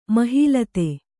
♪ mahī late